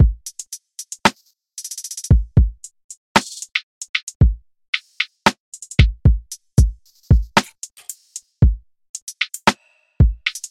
鼓声循环
描述：这是一个不含808的免费鼓声循环，适用于rap或lofi。
Tag: 114 bpm Trap Loops Drum Loops 1.77 MB wav Key : C Mixcraft